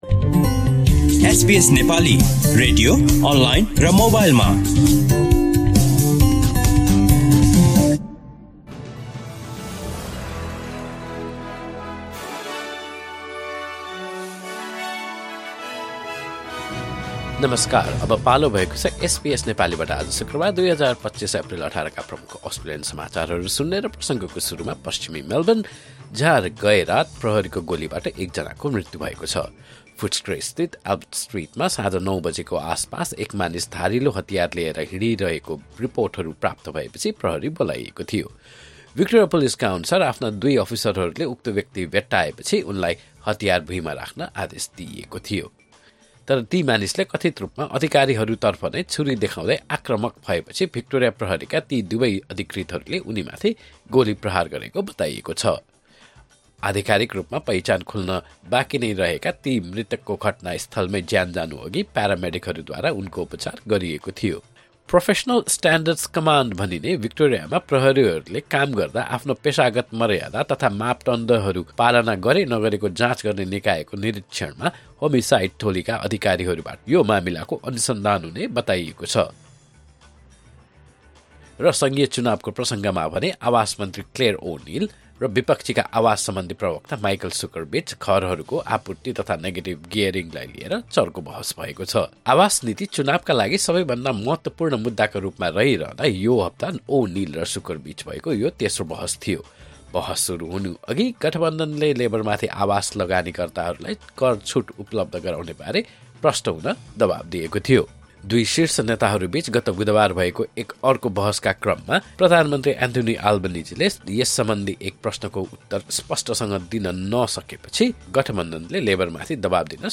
SBS Nepali Australian News Headlines: Friday, 18 April 2025